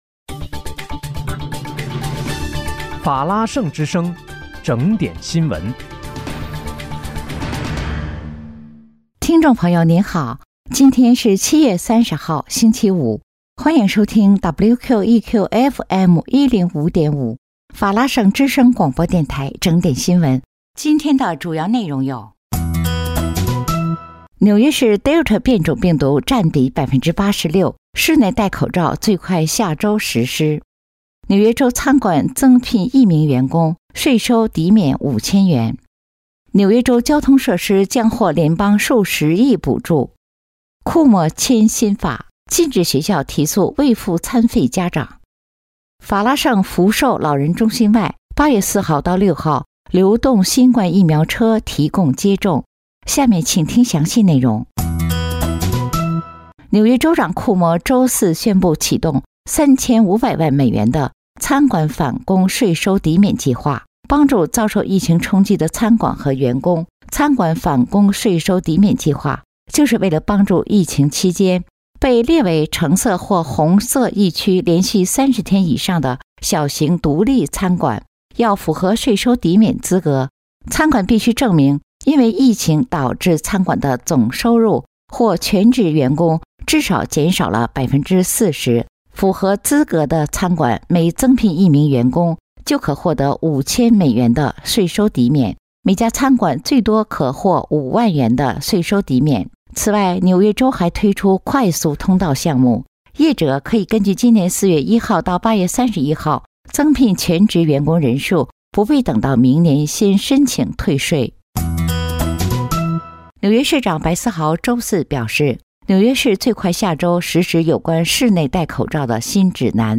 7月30日（星期五)纽约整点新闻